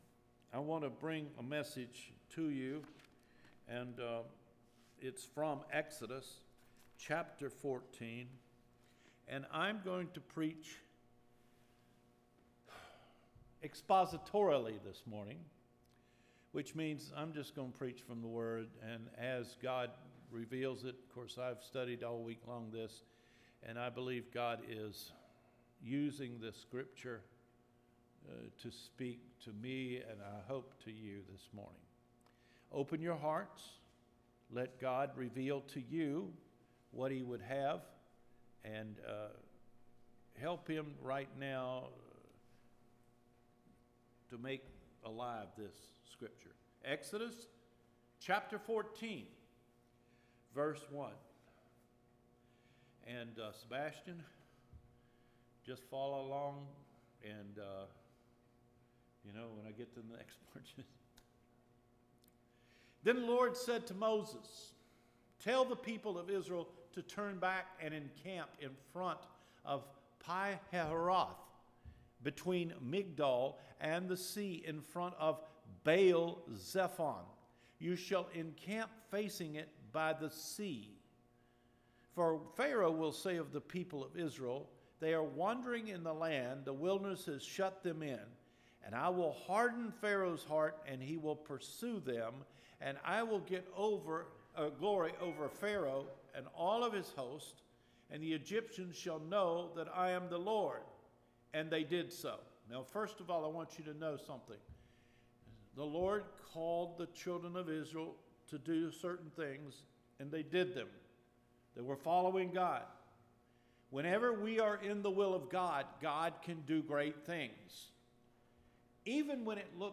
NOVEMBER 15 SERMON – SEEING THE POWER OF GOD (DELIVERED FROM SLAVERY) – Cedar Fork Baptist Church